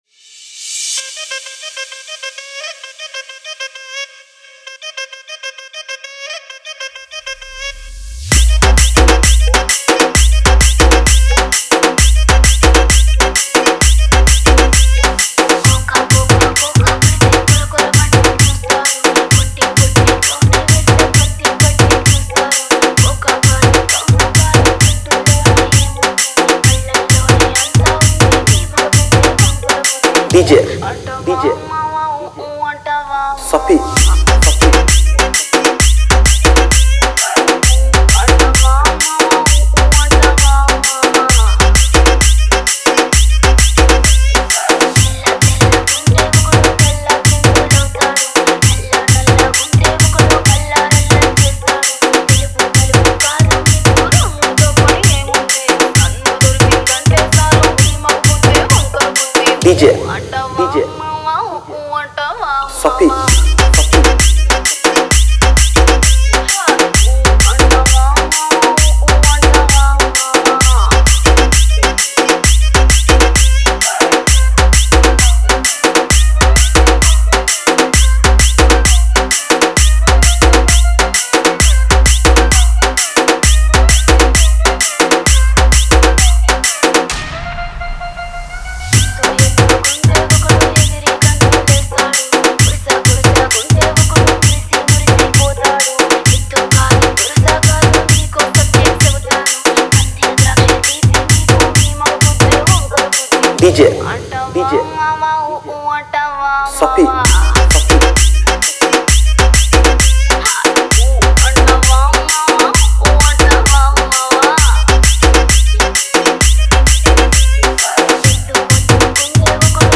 Telug Dj Collection 2022 Songs Download